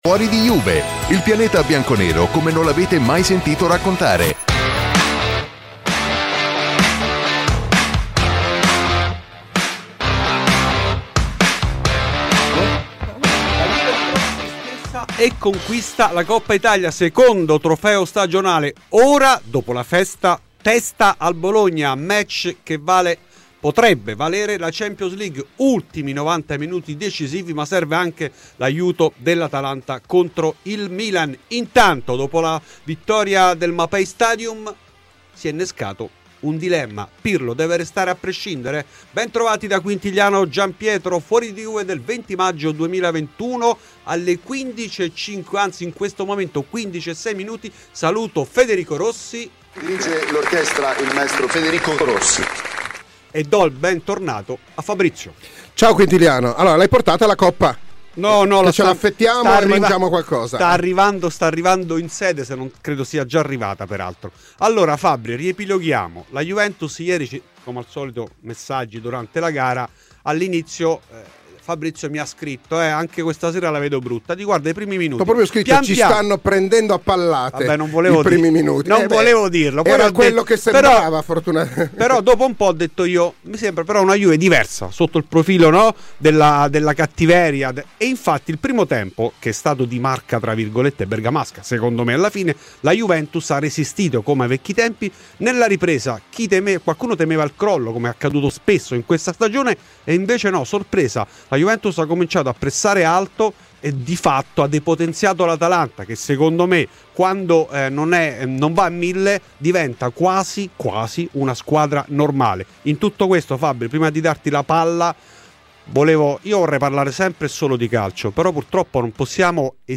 Clicca sul podcast in calce per ascoltare la trasmissione integrale.